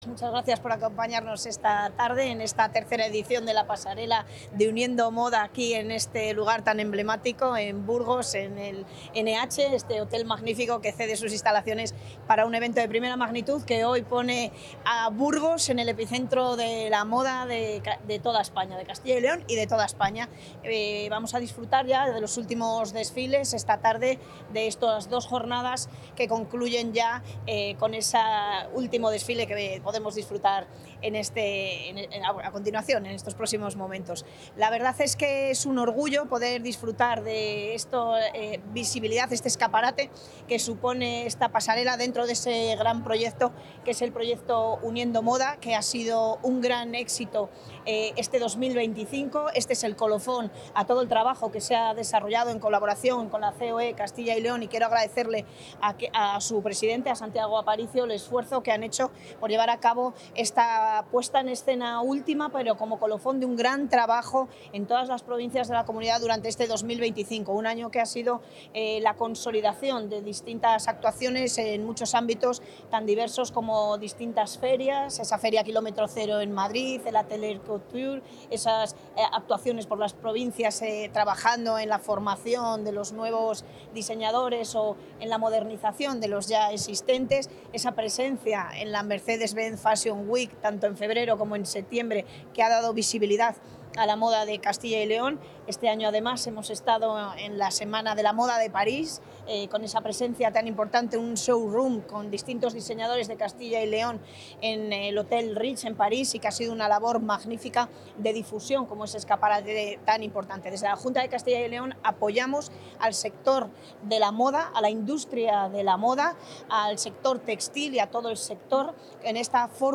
Intervención de la consejera.
El premio ha sido entregado esa tarde por la consejera de Industria, Comercio y Empleo, Leticia García, antes del desfile de clausura de la Pasarela que se celebra en la capital burgalesa desde la jornada de ayer.